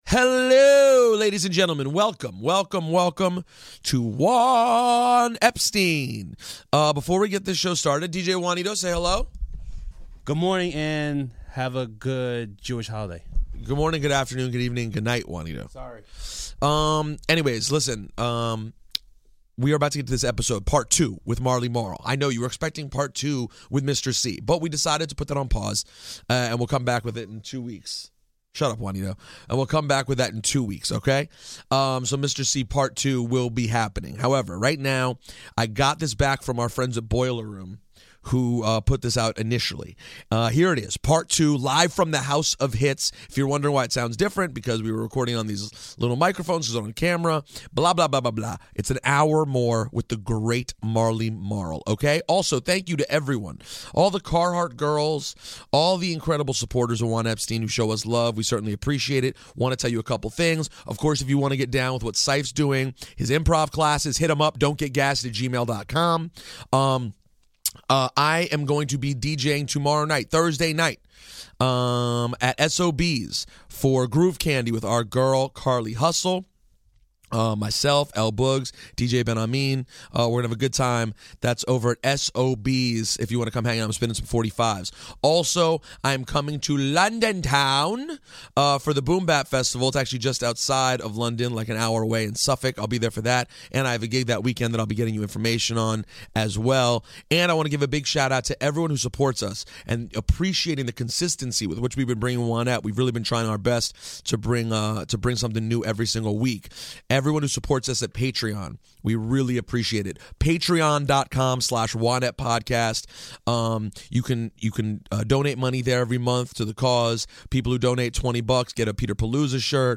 Live from the House of Hits and this time Marley is playing us rarities discussed on Part 1!